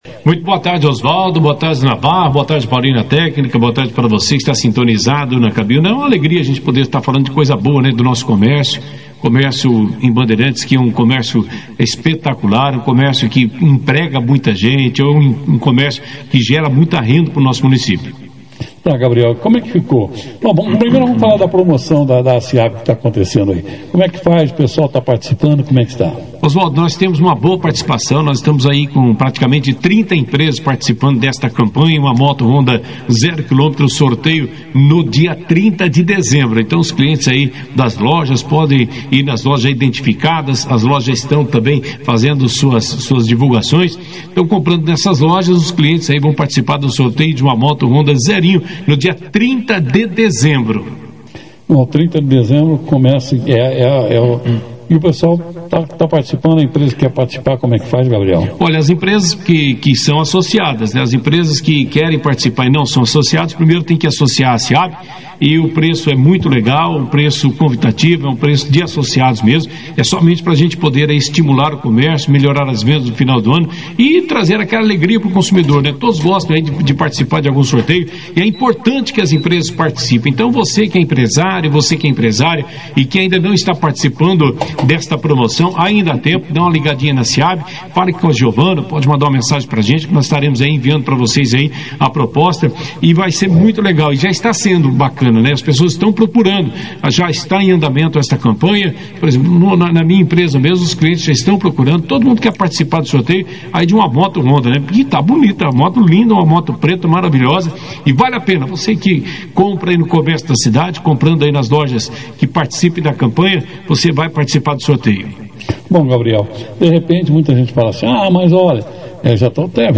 Durante a entrevista, ele falou sobre as datas sugeridas pelos comerciantes associados para o horário de funcionamento noturno do comércio no final de 2025, além da proposta de abertura aos sábados em 2026 e outras reivindicações que serão encaminhadas para aprovação dos sindicatos patronal e dos comerciários.